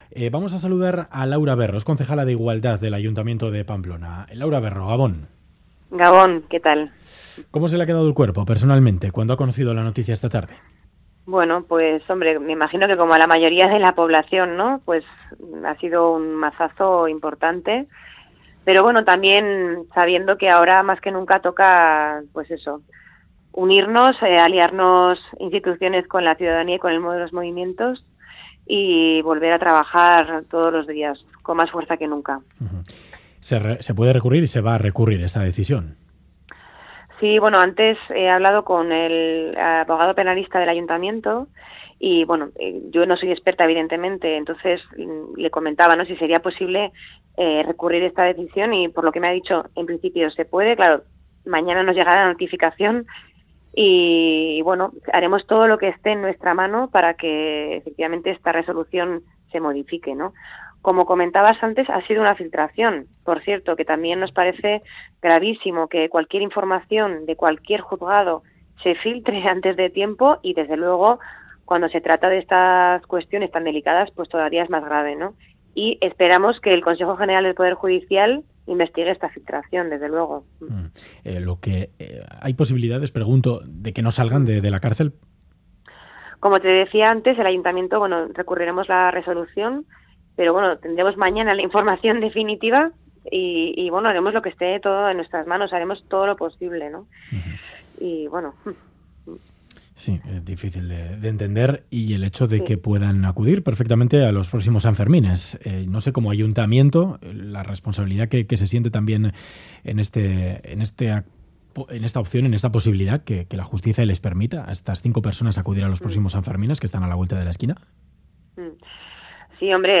Audio: Entrevistada en Ganbara la concejala de igualdad del Ayuntamiento de Pamplona, Laura Berro afirma que 'ha sido un mazazo importante. Ahora más que nunca toca unirnos, aliarnos instituciones con la ciudadanía y movimientos.